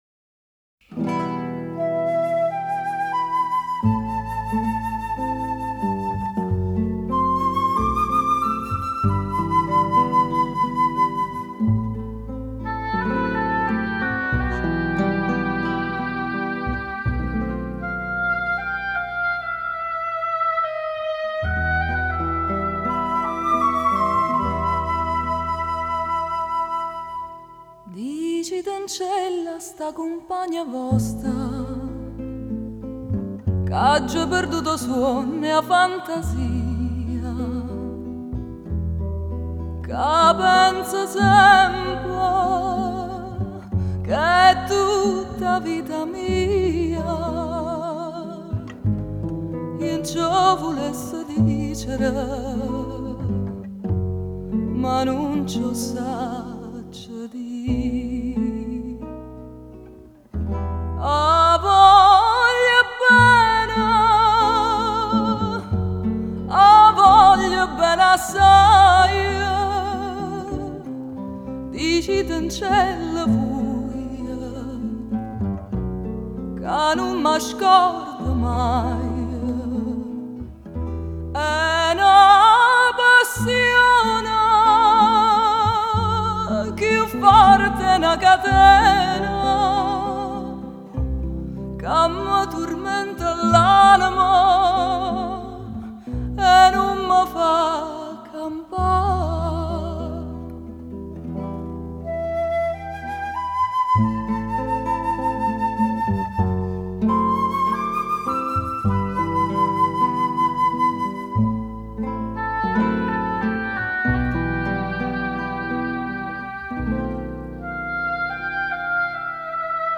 Очень симпатичное исполнение! Такое чувственное и душевное!
И качество записи превосходное!
Такая нежность струится - не наслушаешься!
Впервые слышу эту "мужскую" вещь в женском исполнении.